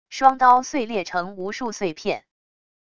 双刀碎裂成无数碎片wav音频